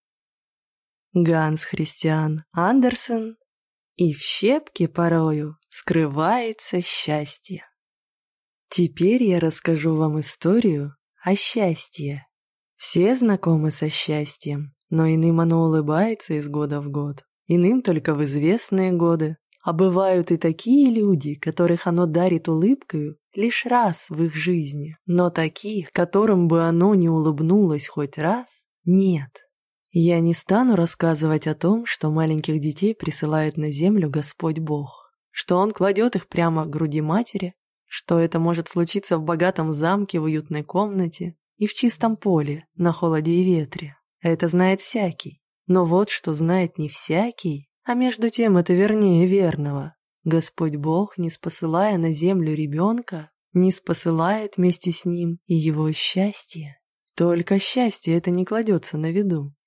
Аудиокнига И в щепке порою скрывается счастье!